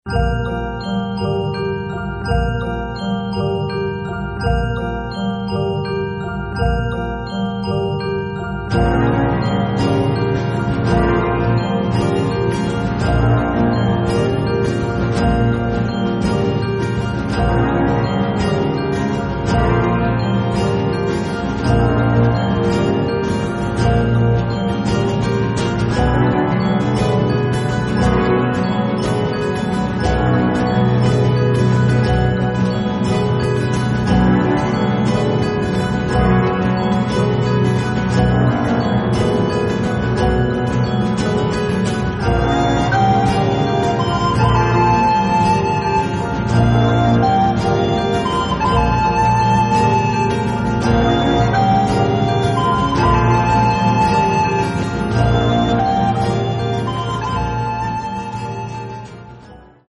Рок
все инструменты, вокал